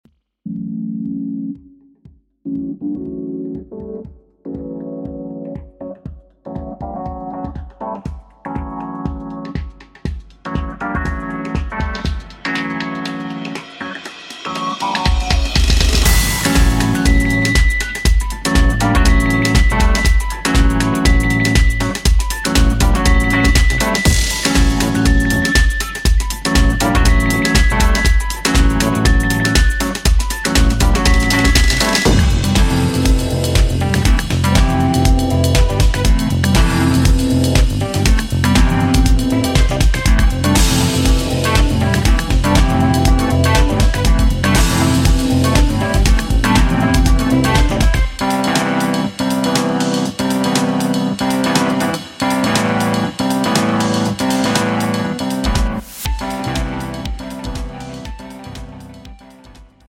Live Sounds